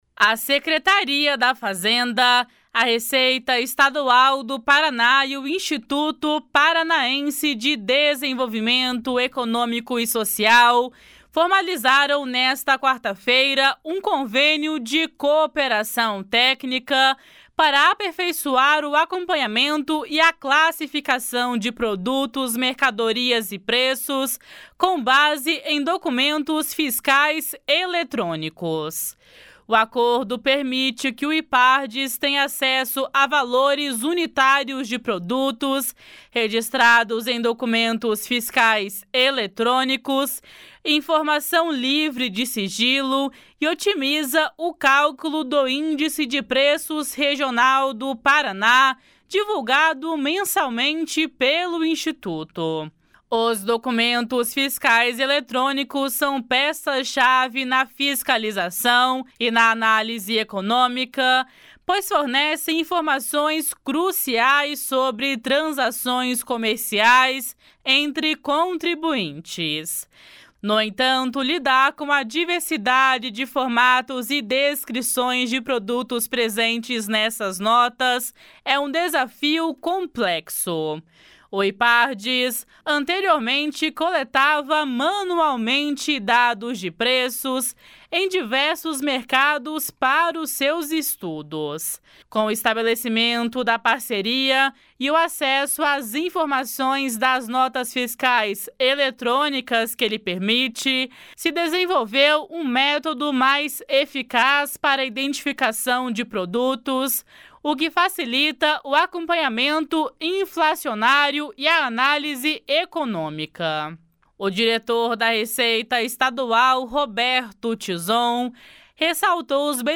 O diretor da Receita Estadual, Roberto Tizon, ressaltou os benefícios mútuos do compartilhamento de dados. // SONORA ROBERTO TIZON //
Jorge Callado, diretor-presidente do Ipardes, enfatizou a sinergia entre os órgãos envolvidos.